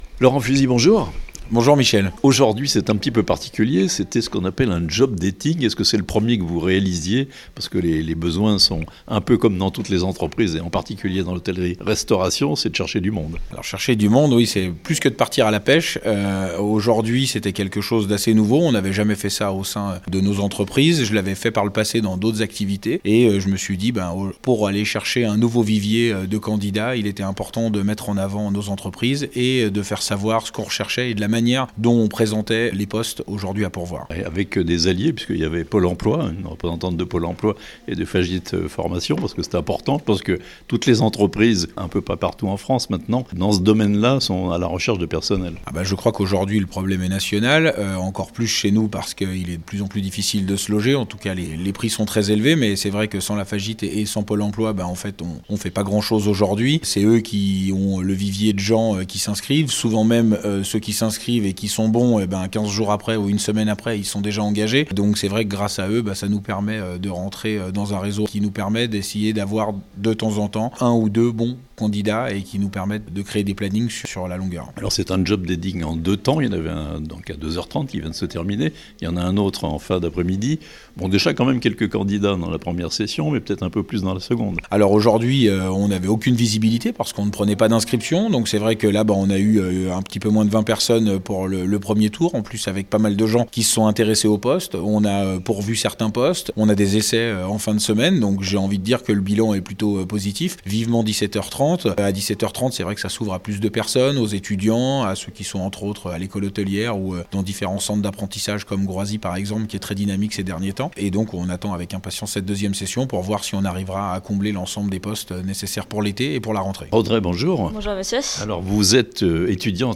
Recrutement du personnel en hôtellerie-restauration, recours au "job dating" (interview)